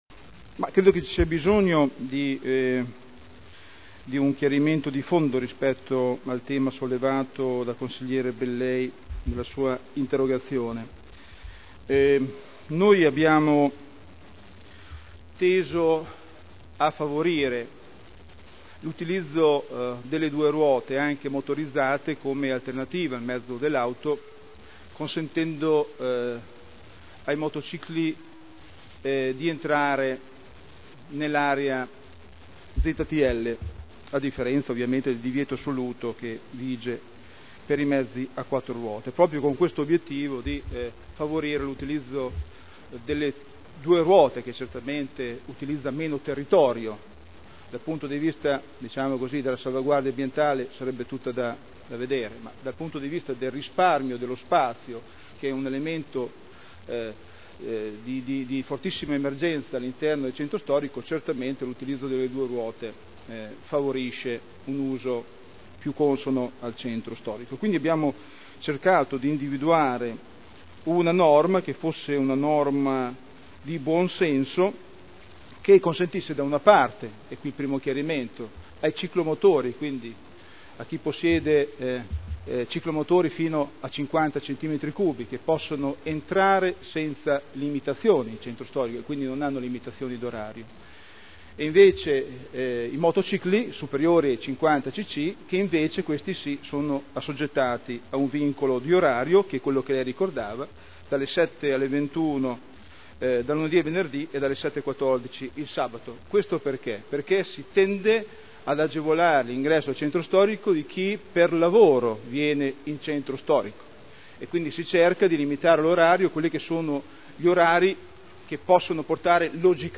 Sitta risponde a Bellei — Sito Audio Consiglio Comunale
Seduta del 09/11/2009. Confusione nell'accesso dei motocicli alla zona ZTL